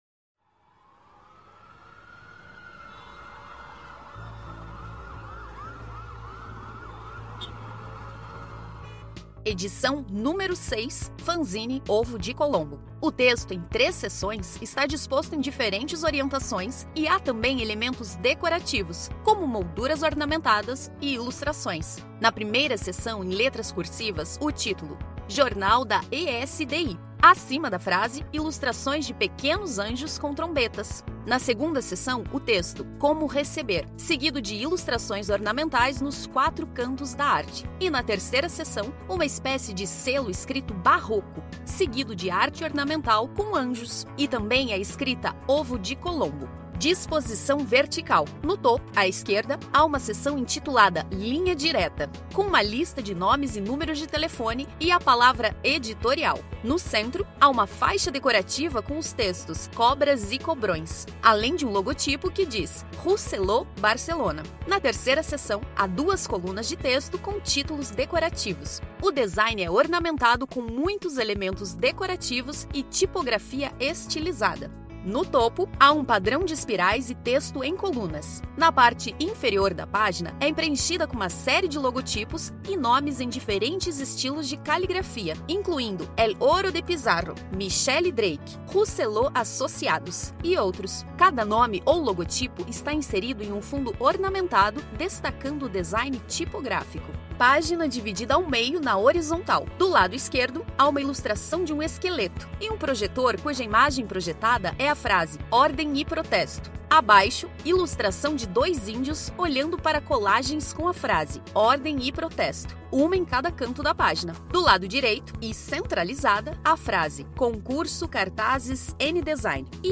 Audiodescrição do Fanzine n° 6